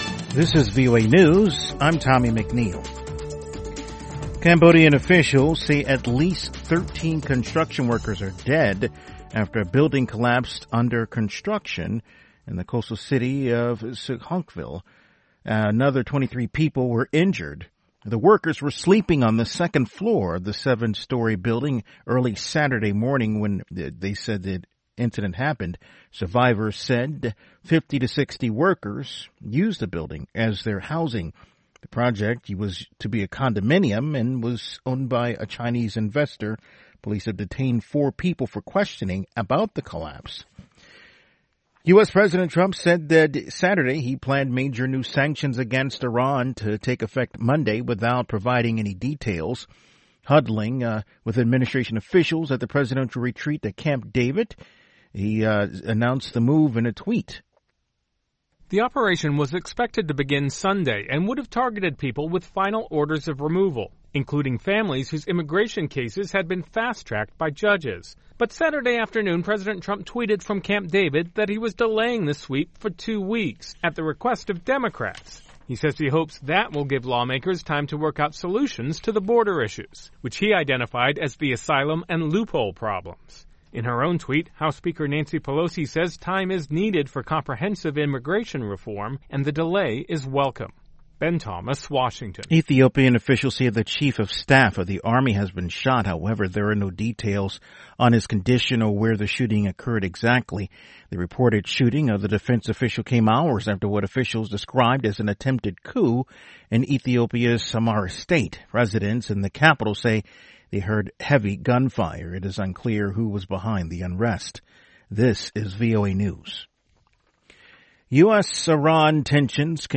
We bring you reports from our correspondents and interviews with newsmakers from across the world.
Tune in at the top of every hour, every day of the week, for the 5-minute VOA Newscast.